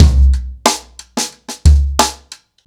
• 121 Bpm Drum Beat C Key.wav
Free drum loop - kick tuned to the C note.
121-bpm-drum-beat-c-key-c7W.wav